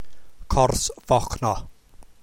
Para escuchar cómo se pronuncia Cors Fochno, presione play: